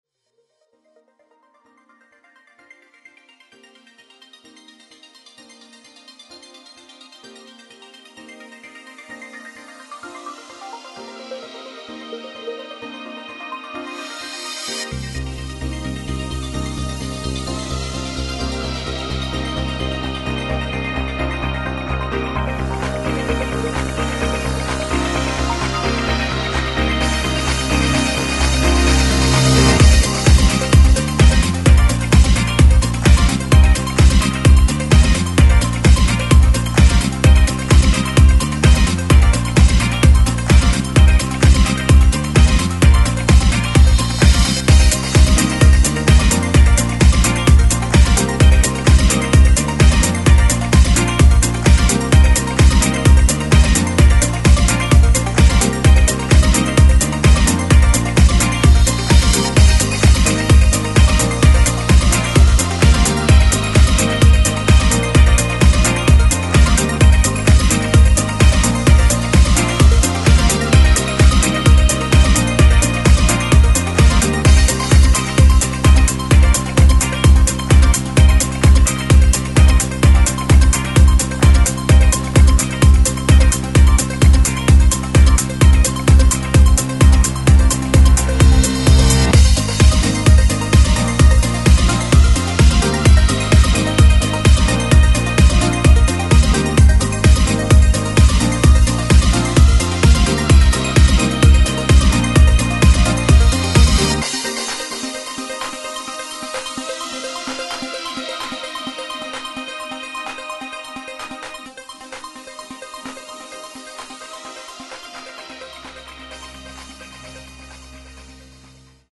Click on the cover and listen to the instrumental mix.